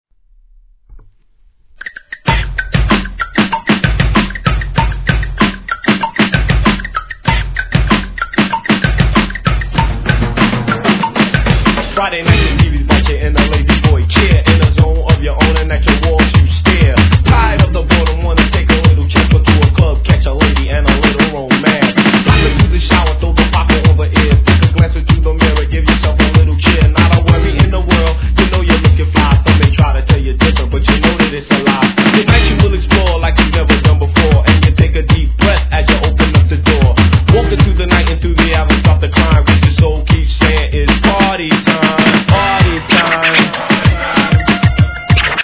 HIP HOP/R&B
'88年オールド・スクール！！